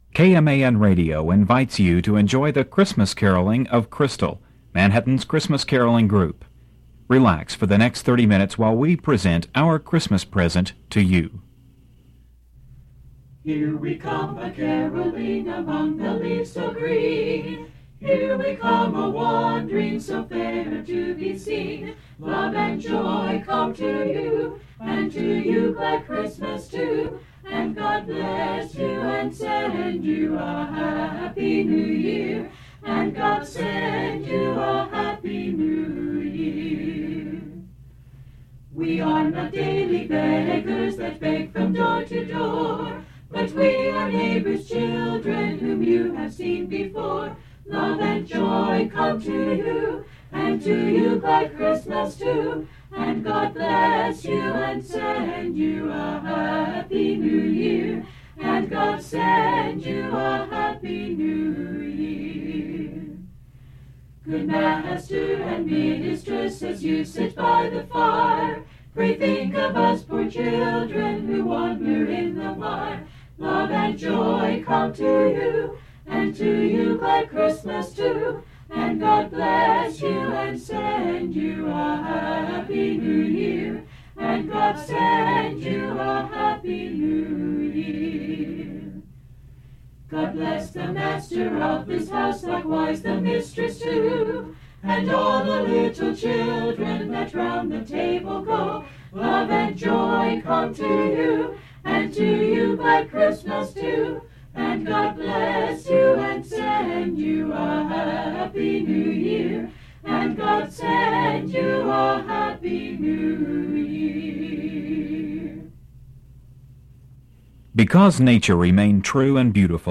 Special Vintage Christmas Radio Program
Christmas Caroling group
The group performed a cappella traditional Christmas music in Manhattan, Wamego, and Topeka, Kansas.